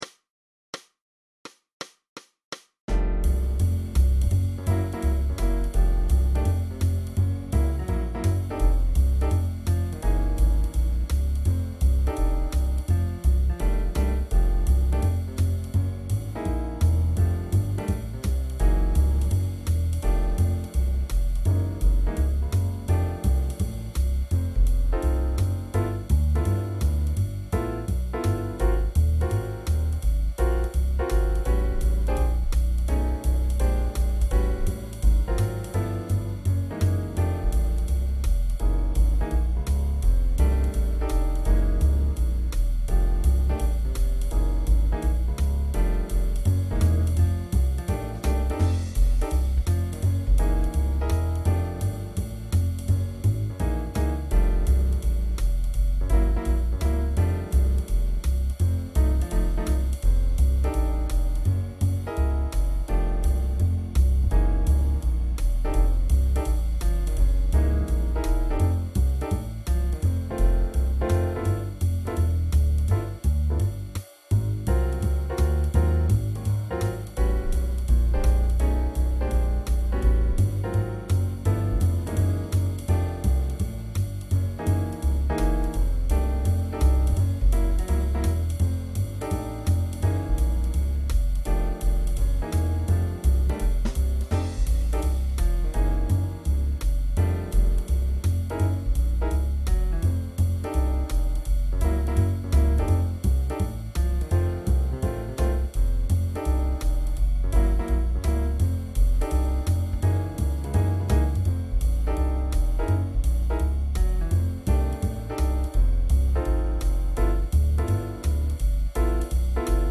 High School Jazz Band Practice Tracks 2007-2008
Each tune has a two bar intro (drums)